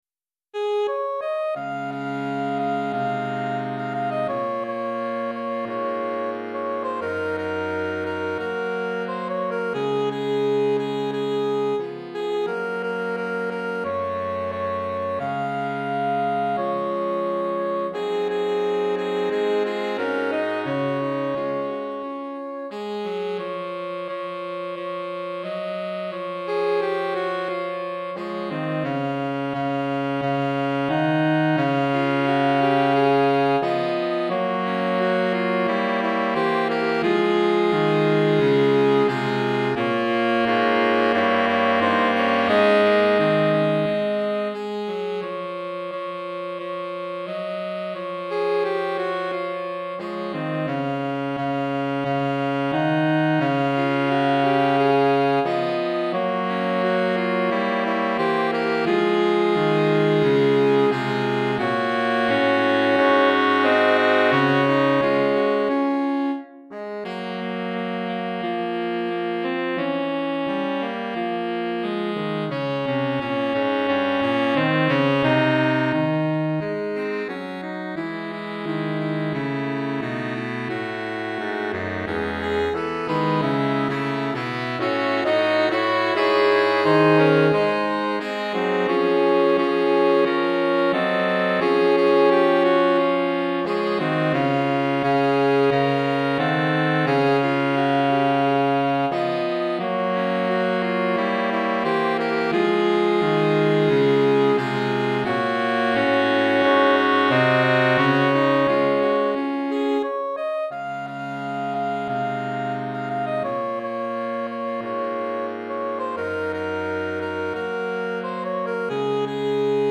Saxophone Soprano, Saxophone Alto, Saxophone Ténor